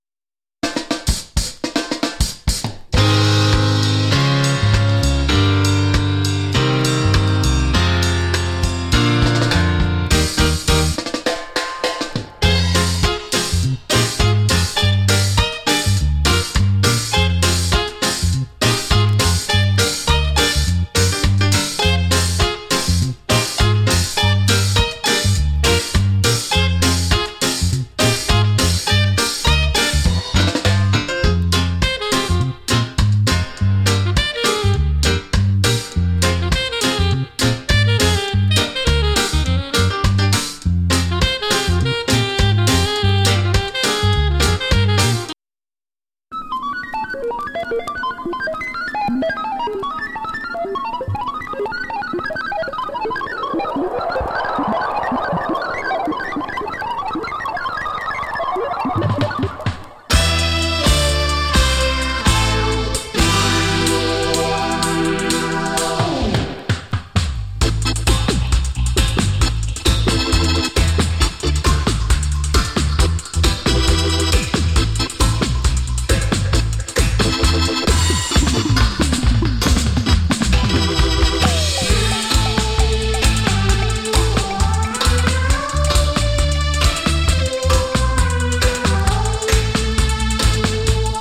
ドープでありながら中毒性の高いポップさも兼ね備えている。